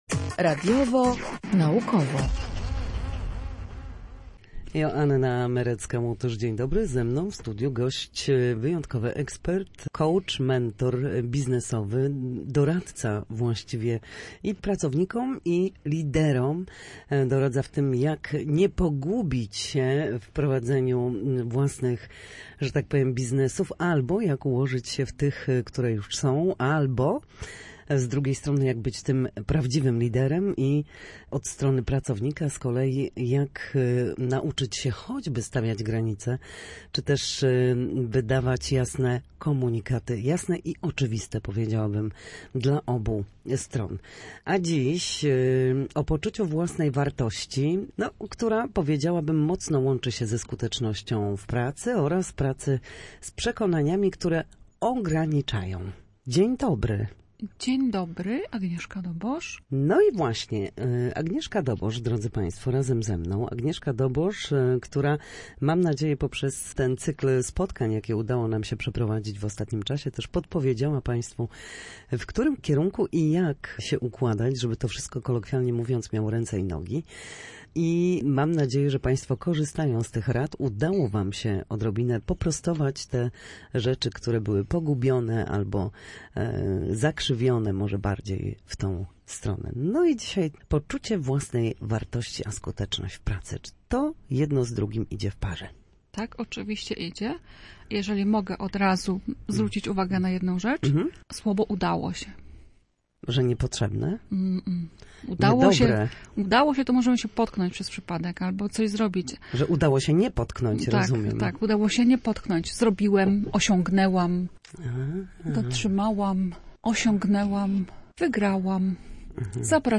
Na naszej antenie mówiła o tym, jak ogromne znaczenie w pracy ma poczucie własnej wartości.